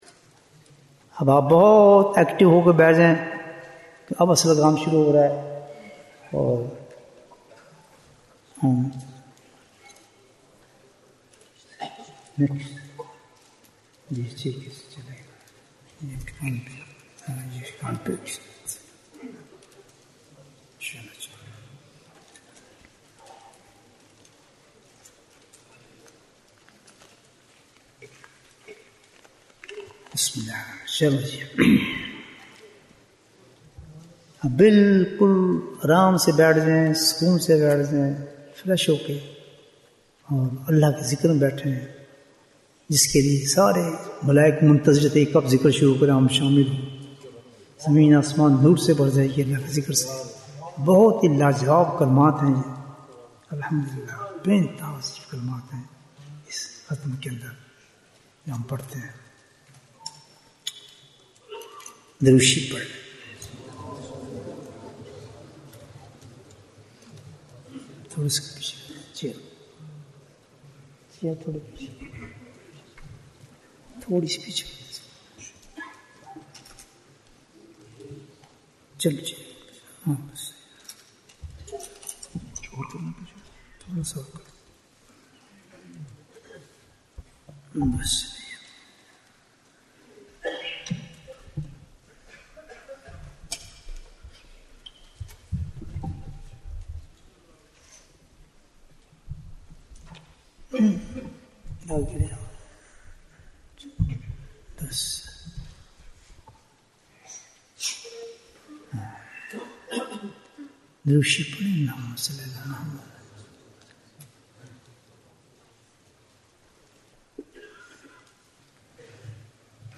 Annual Ijtema Dhikr Majlis 2025 Bayan, 53 minutes25th December, 2025